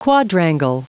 Prononciation du mot quadrangle en anglais (fichier audio)
Prononciation du mot : quadrangle